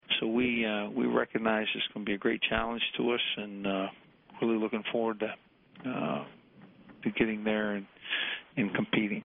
LSU Head Coach Les Miles